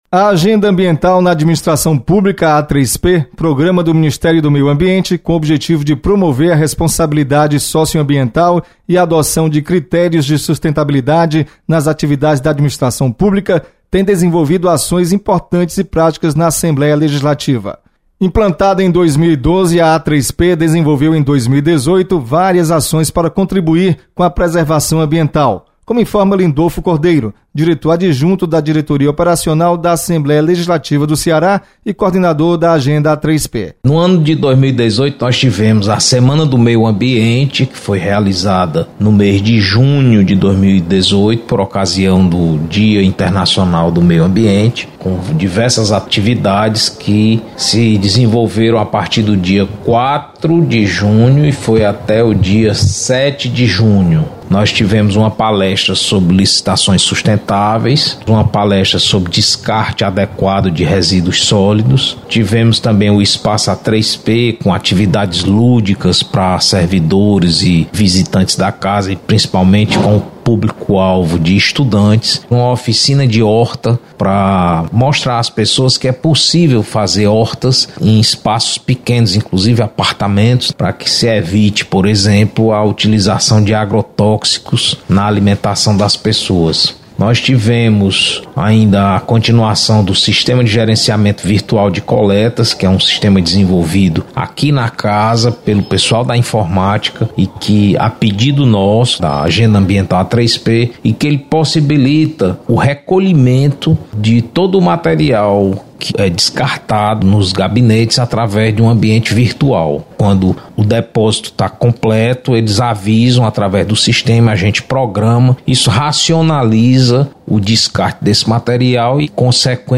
programa A3P comemora resultados positivos de políticas adotadas em 2018. Repórter